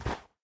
sounds / step / snow2.ogg
snow2.ogg